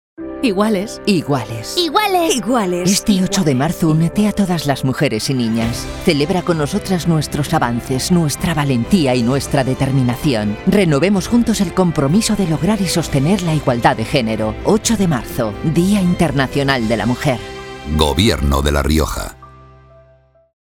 Cuña radiográfica